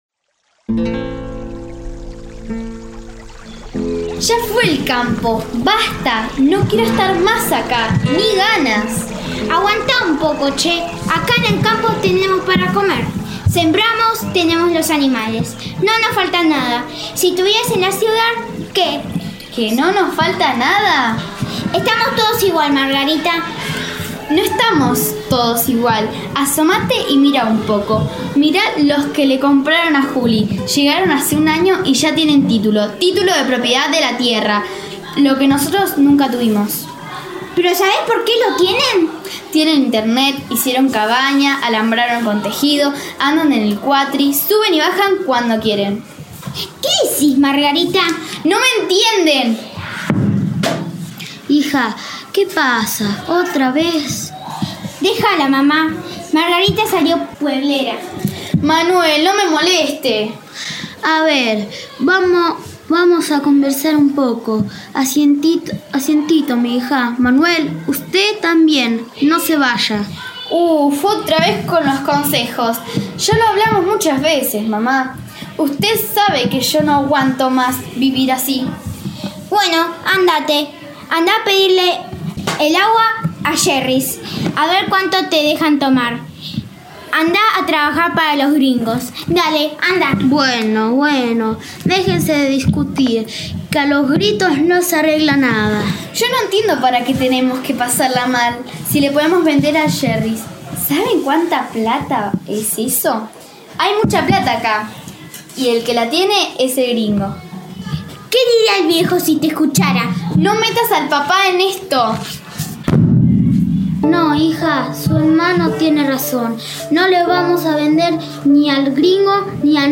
i) Spots radiales: A partir de la escucha de los audios radiales, utilizarlos como disparadores para generar investigaciones, escritos literarios o de no ficción.
Producciones de radio realizadas por
WATER escena margarita y manuel discuten.mp3